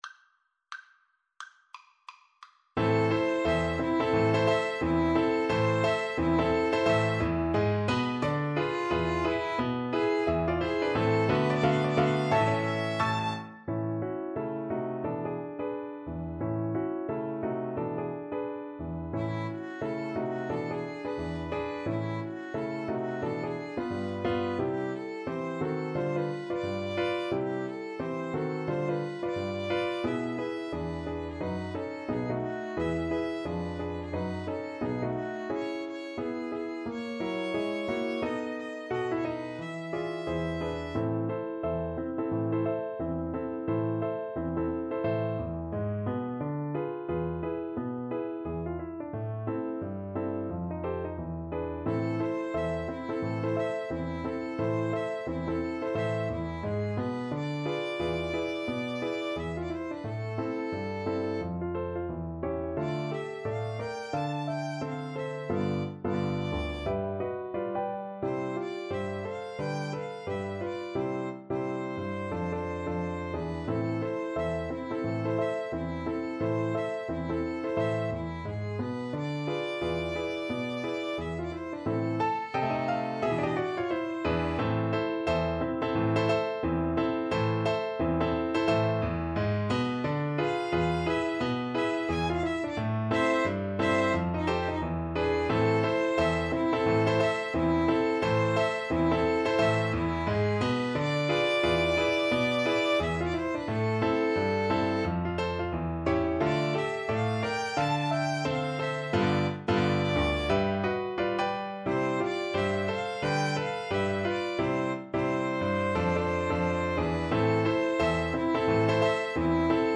~ = 176 Moderato
Jazz (View more Jazz Piano Trio Music)